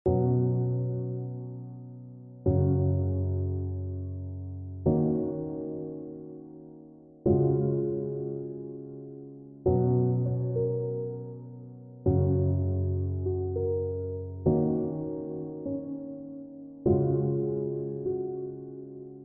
Soft Piano